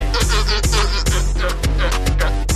Sea Lion Sound Meme MP3